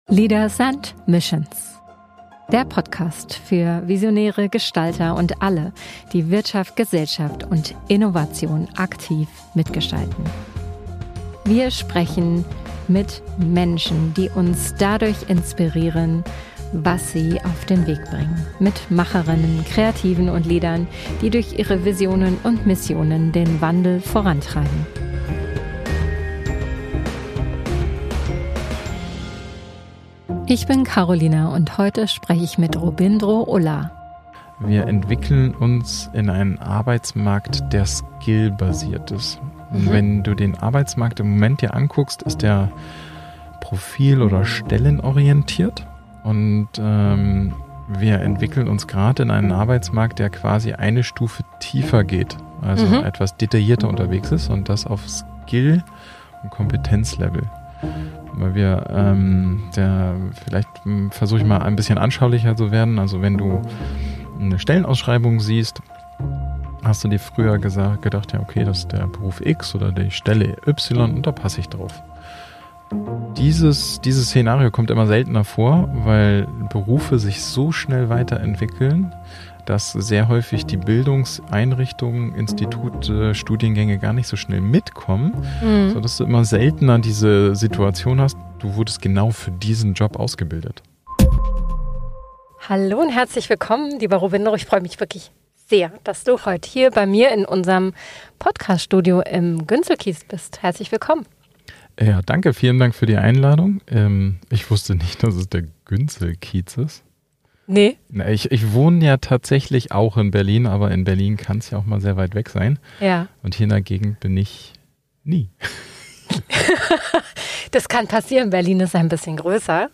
Ein Gespräch über New Work, New Media & New Mindsets.